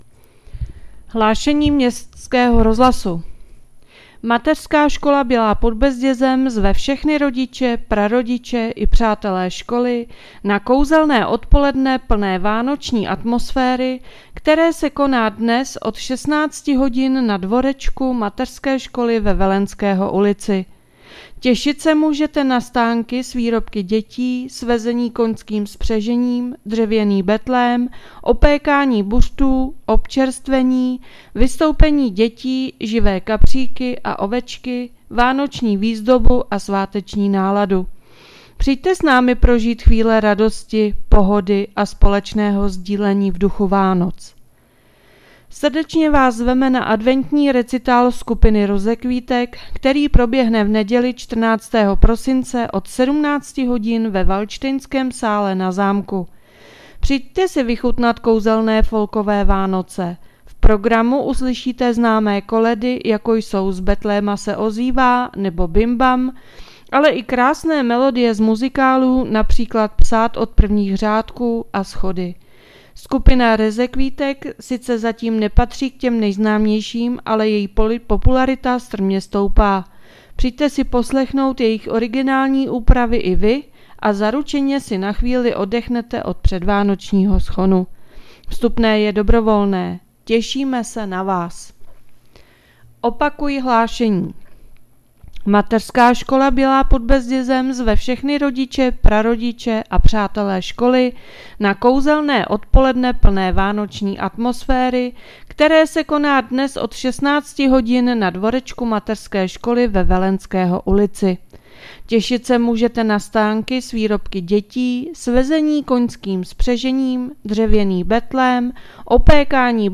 Hlášení městského rozhlasu 10.12.2025